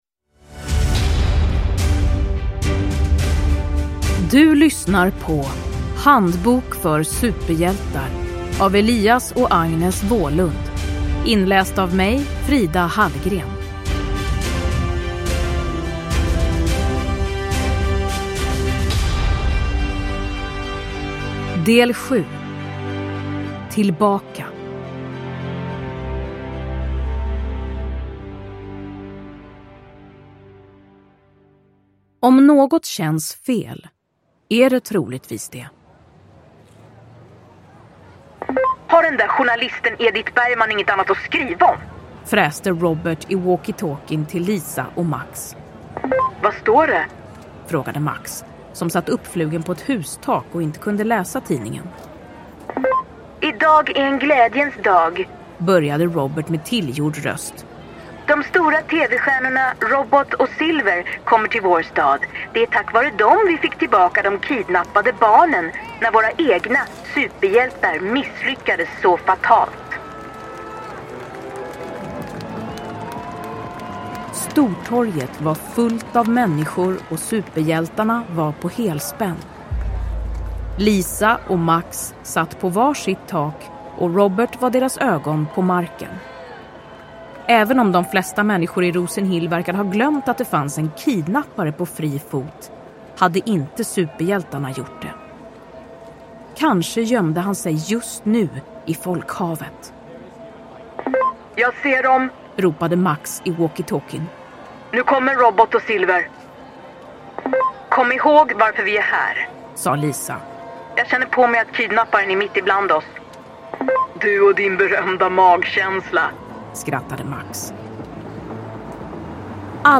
Handbok för superhjältar. Tillbaka – Ljudbok – Laddas ner
Uppläsare: Frida Hallgren